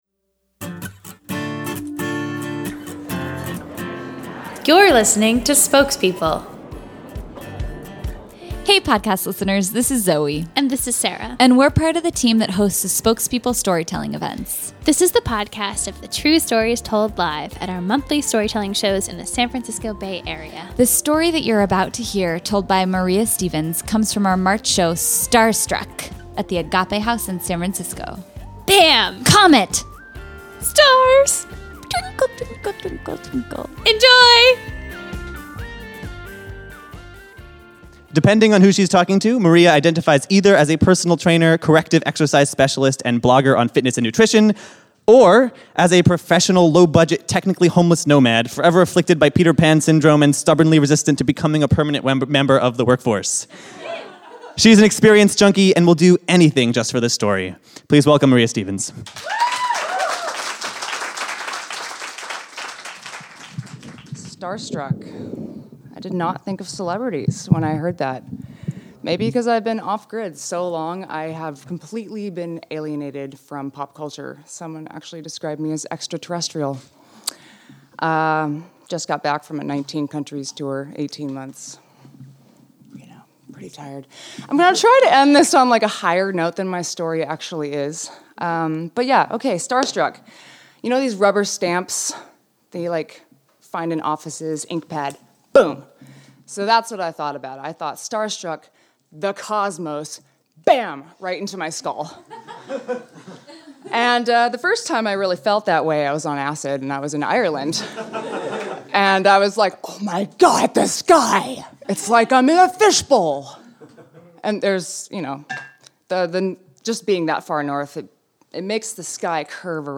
One starry night in the french countryside, one world traveler finds love where she most wanted it, but least expected it. Enjoy this story of her ethereal love affair that was told live at our March 2014 show, Starstruck.